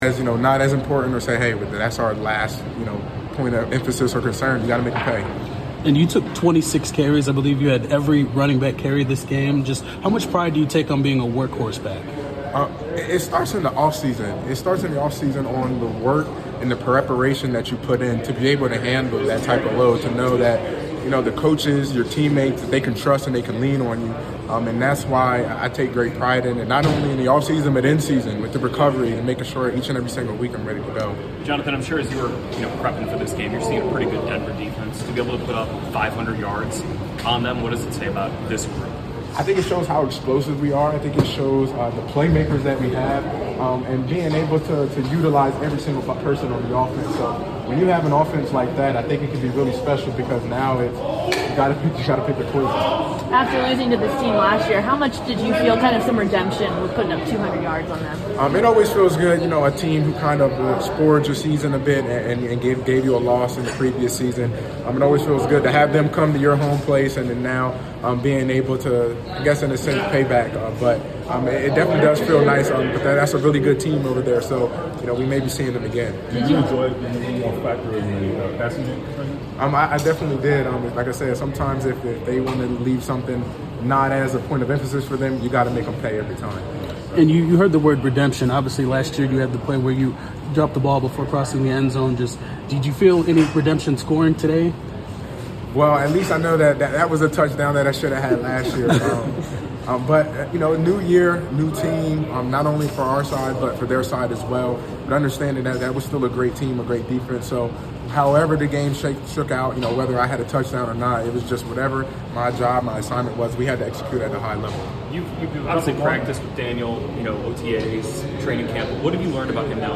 Indianapolis Colts Running Back Jonathan Taylor Postgame Interview after defeating the Denver Broncos at Lucas Oil Stadium.